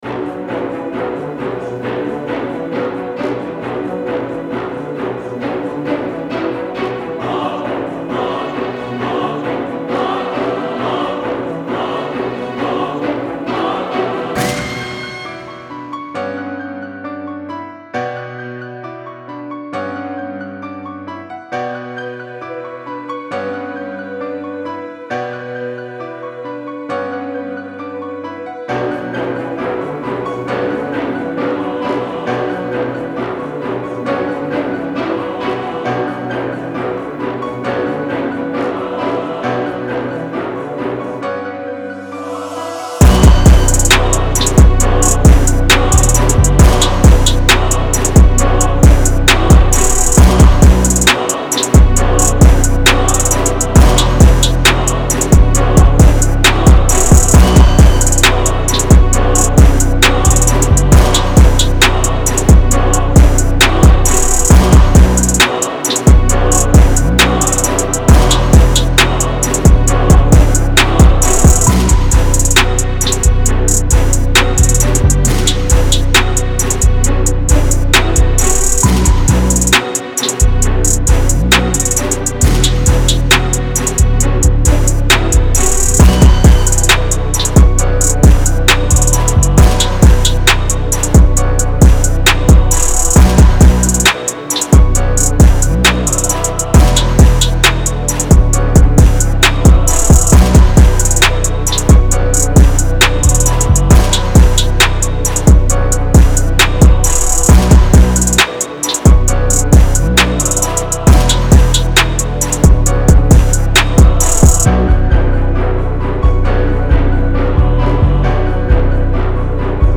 由五个史诗般的Trap节拍组成